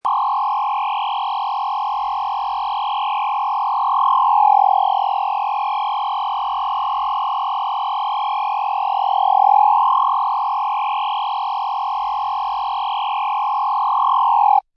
Download Free Laser Sound Effects
Laser